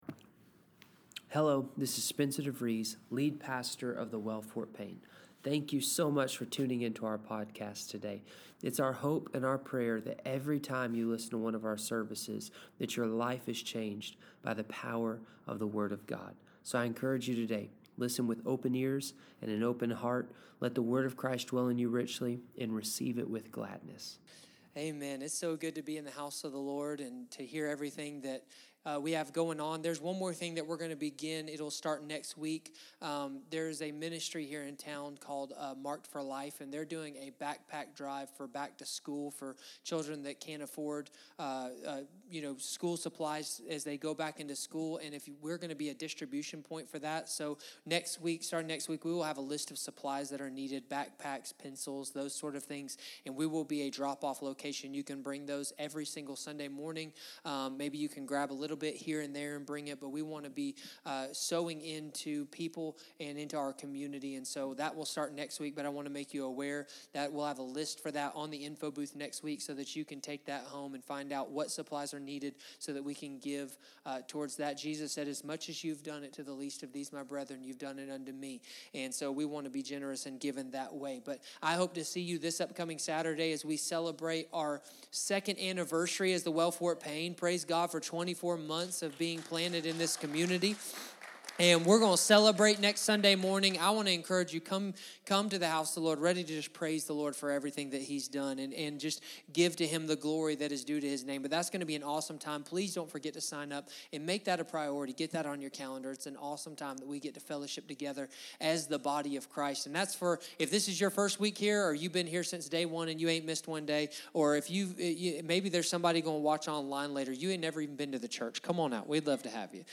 Sermons | The Well Fort Payne